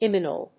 音節Im・man・u・el 発音記号・読み方
/ɪmˈænjuəl(米国英語)/